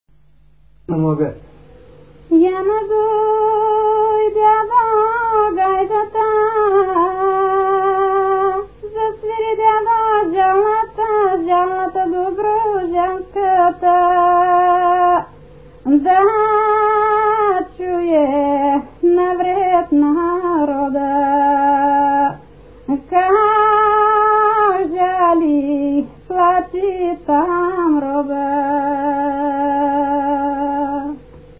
музикална класификация Песен
размер Безмензурна
фактура Едногласна
начин на изпълнение Солово изпълнение на песен
фолклорна област Североизточна България
място на записа Житница
начин на записване Магнетофонна лента